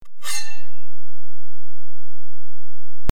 Royalty free sound: Drawing Sword 2
Sound of drawing a sword from its sheath (WAV file)
48k 24bit Stereo
Try preview above (pink tone added for copyright).